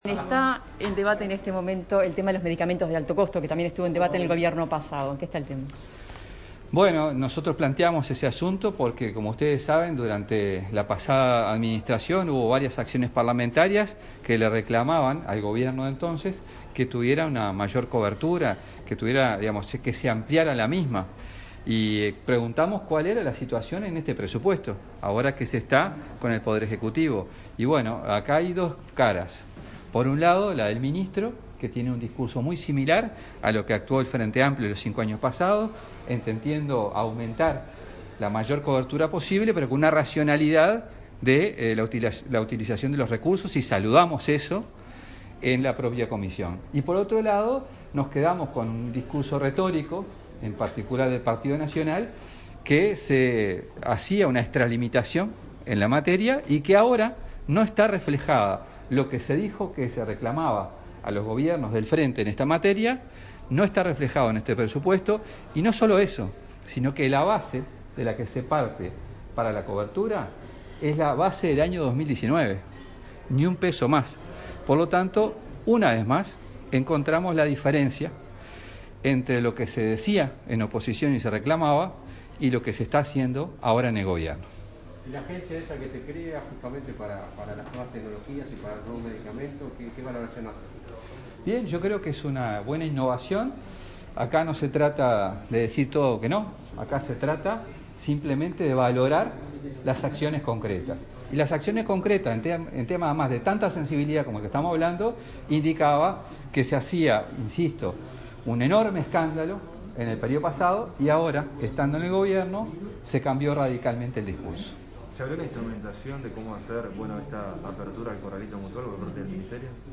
Se encuentra disponible audio completo con las declaraciones